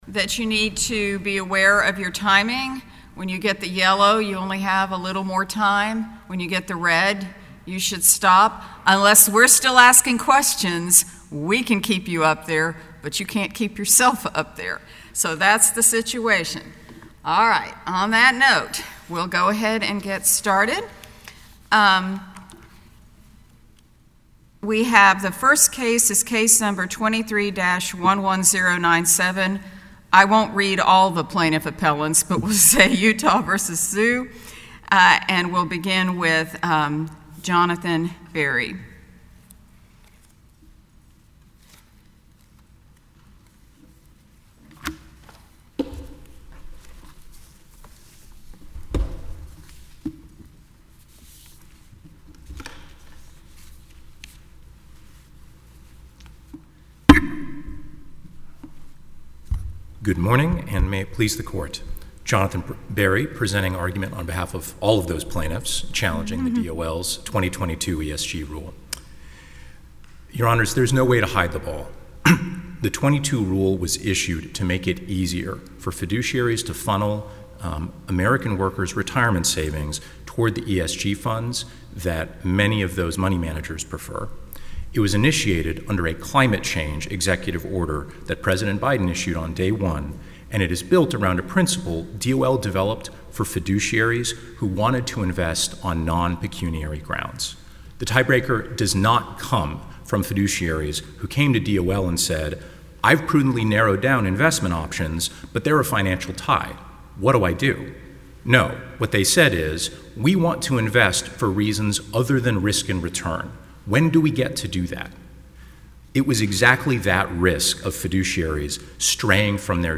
Attorneys representing the Department of Labor and challengers opposing its rule allowing pension fund managers to consider ESG factors in investments presented their oral arguments before the U.S. Fifth Circuit of Appeals Tuesday.